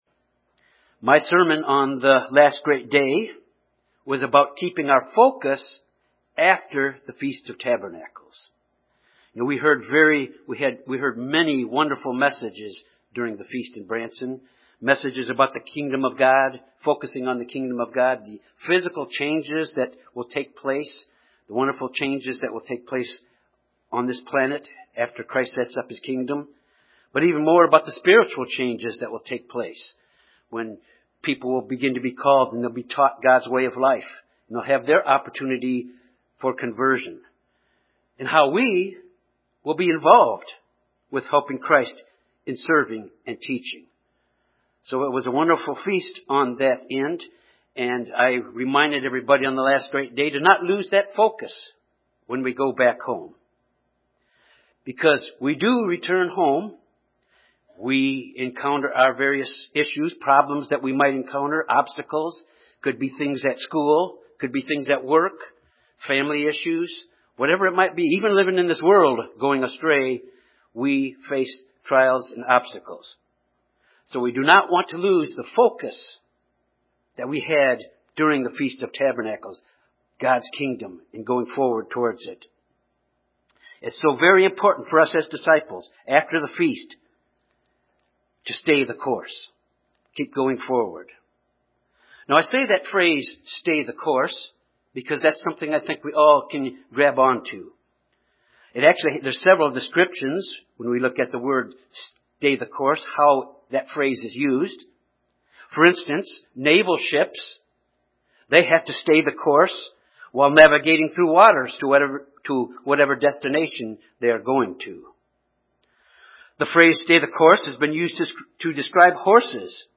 As we return from the Feast, we must go forward to complete the task we’ve been called to do. This sermon emphasizes the importance of going forward in spiritual growth this year and staying the course and keep our hand to the plow.